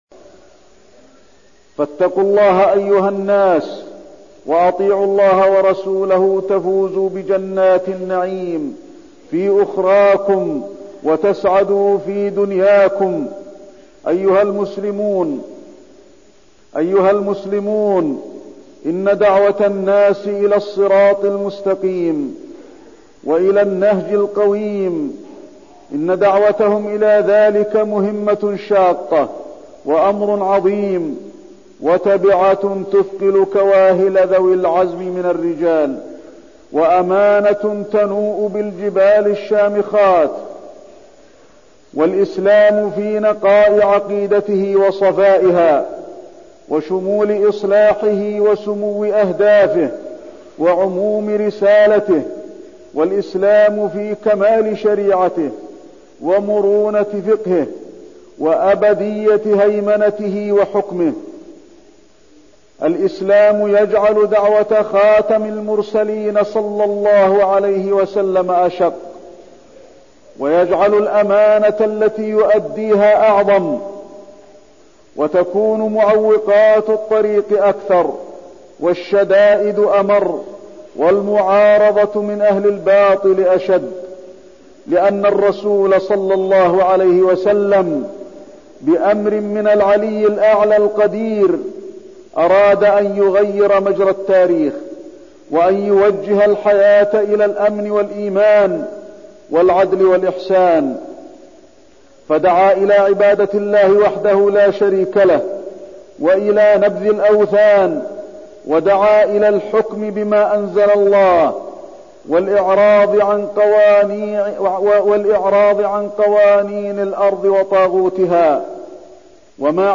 تاريخ النشر ٢٨ رجب ١٤١٠ هـ المكان: المسجد النبوي الشيخ: فضيلة الشيخ د. علي بن عبدالرحمن الحذيفي فضيلة الشيخ د. علي بن عبدالرحمن الحذيفي الإسراء والمعراج